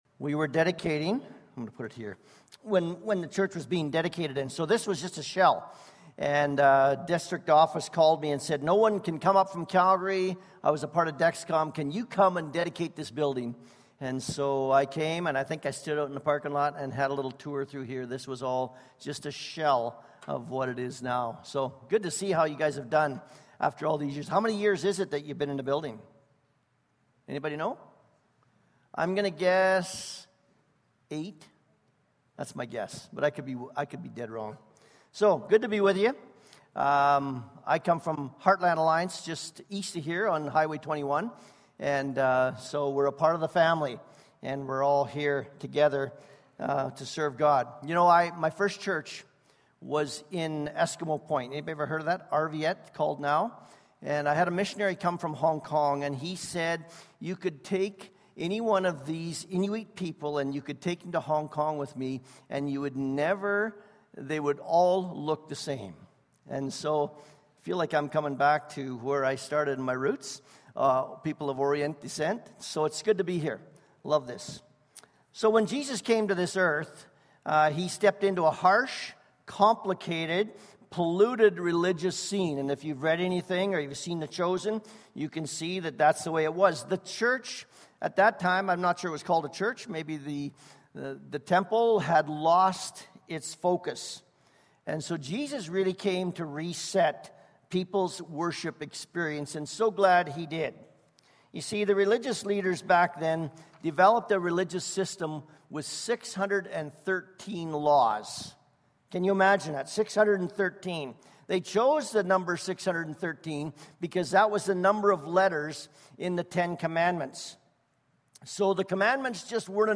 16-17 Service Type: Sunday Morning Service Passage